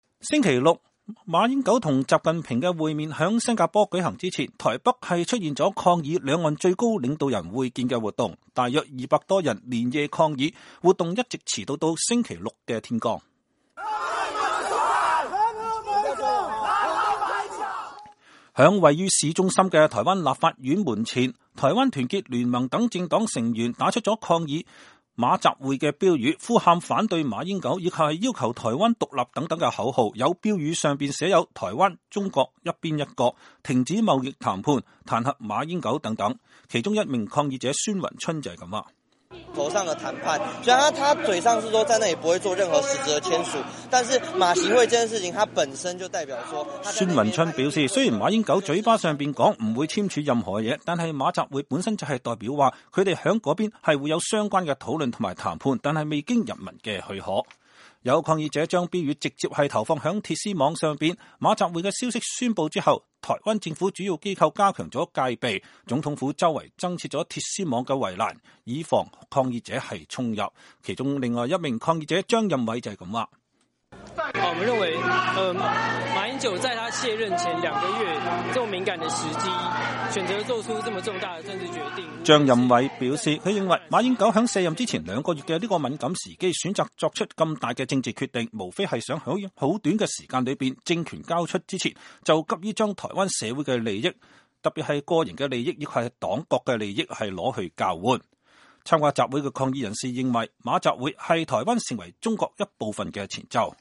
在位於市中心的台灣立法院門前，台灣團結聯盟等政黨成員，打出抗議馬-習會標語，呼喊反對馬英九，以及要求台灣獨立等口號。